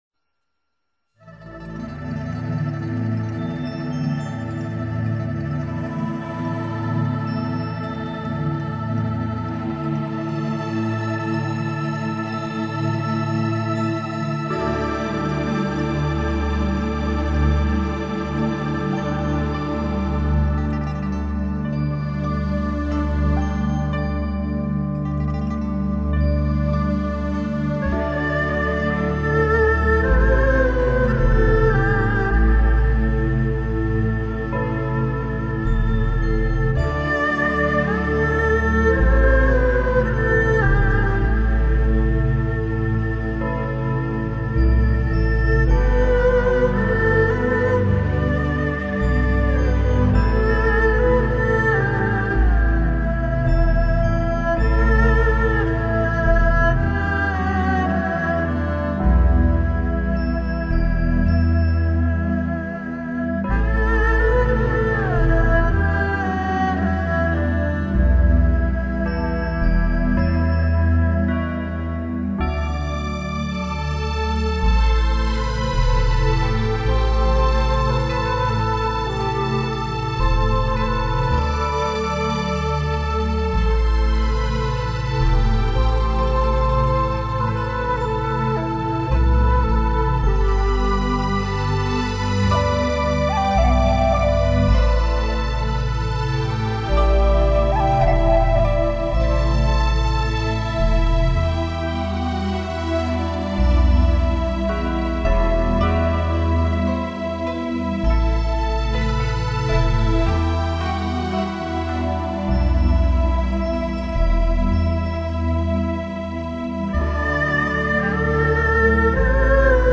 专辑语种：佛音专辑1CD